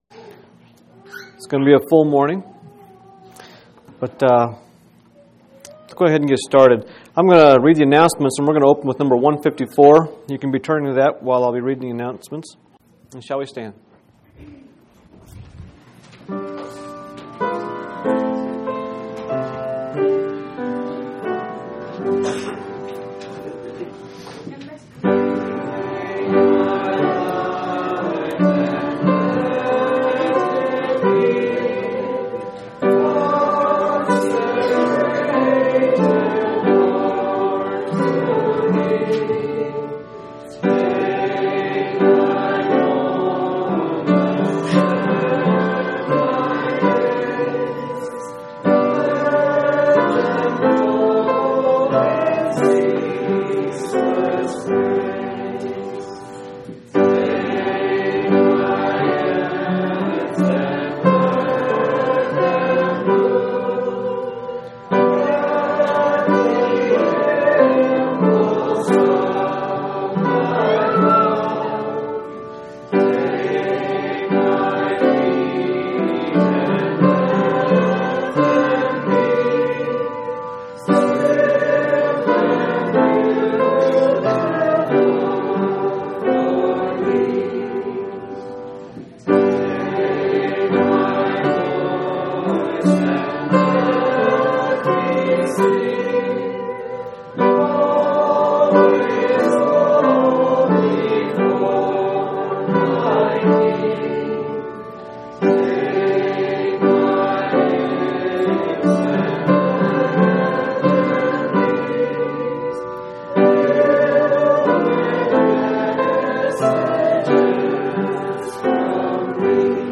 7/11/2004 Location: Phoenix Local Event